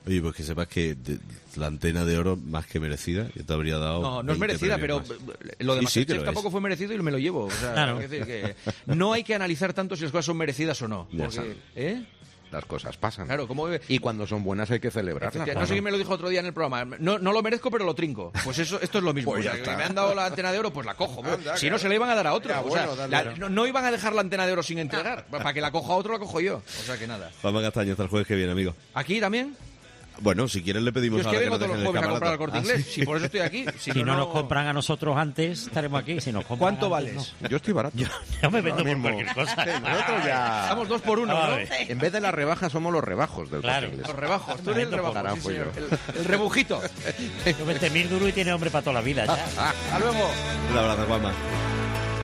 "No es merecida", decía Castaño entre risas sobre la Antena de Oro.